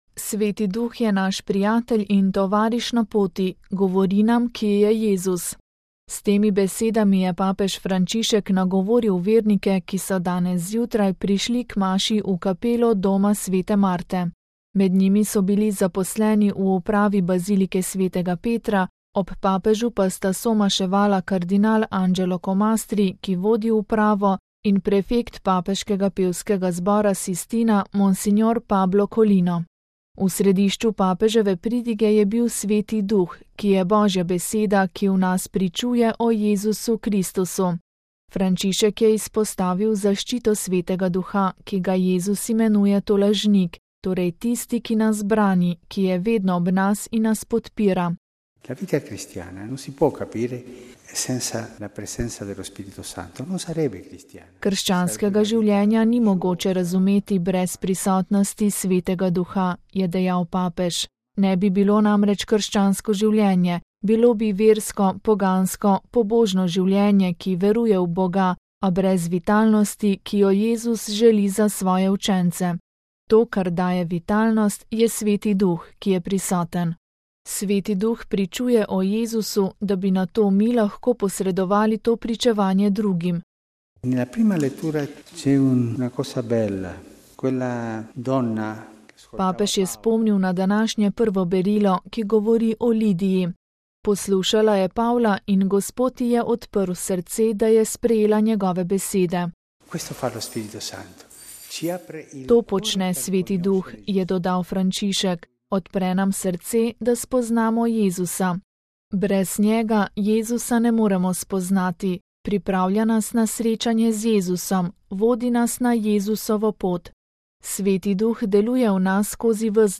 Med jutranjo mašo papež govoril o Svetem Duhu
S temi besedami je papež Frančišek nagovoril vernike, ki so danes zjutraj prišli k maši v kapelo Doma sv. Marte.